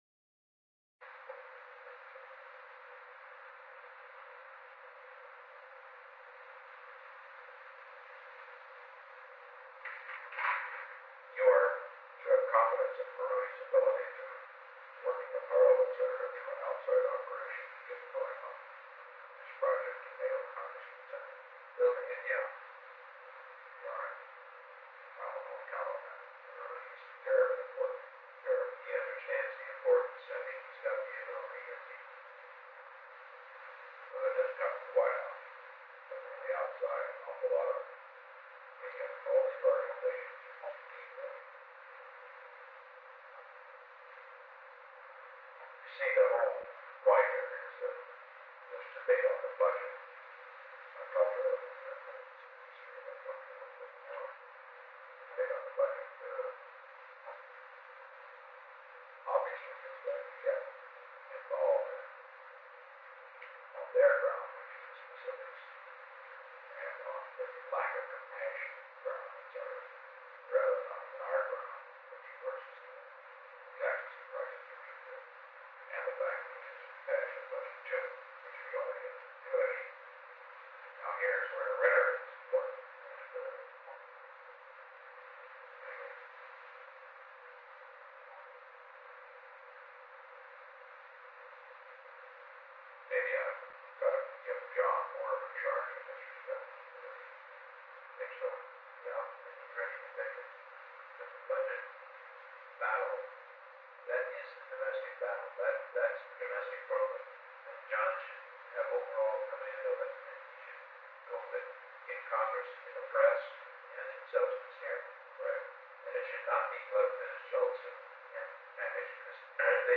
Kissinger and Ziegler left at 11:15 am.
Haldeman left at 11:50 am.
Secret White House Tapes |